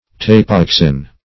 Search Result for " tapayaxin" : The Collaborative International Dictionary of English v.0.48: Tapayaxin \Ta`pa*yax"in\, n. (Zool.) A Mexican spinous lizard ( Phrynosoma orbiculare ) having a head somewhat like that of a toad; -- called also horned toad .